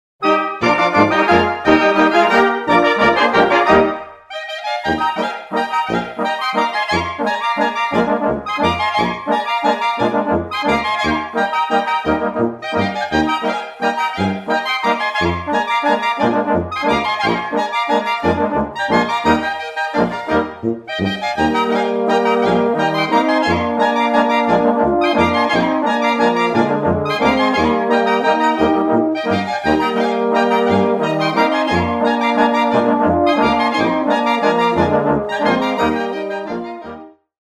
Ein wahrhaft bunter Reigen mit überlieferter und für den heutigen Gebrauch neugestalteter dörflicher Blasmusik!
Klarinettenhalbwalzer in Es - aus den Handschriften von Steinhauser
Doerfliche_Blasmusik_Klarinettenhalbwalzer_in_13.mp3